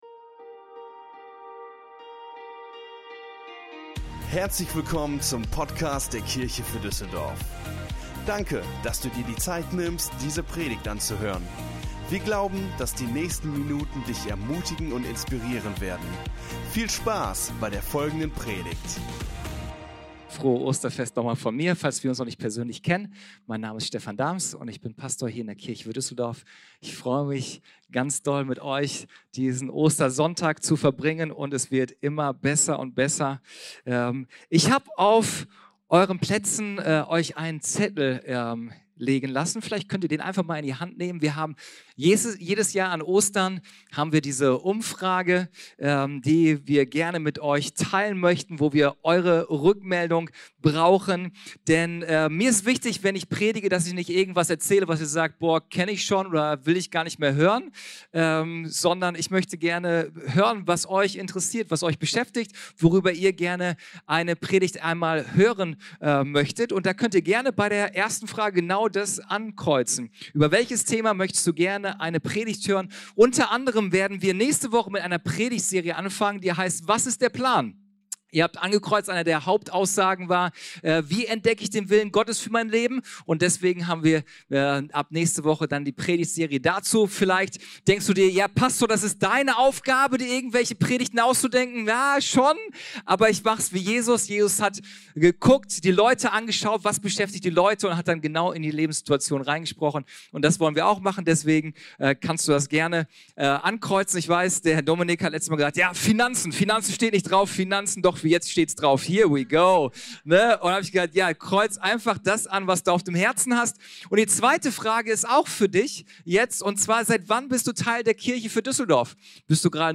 Ostersonntag - Gottesdienst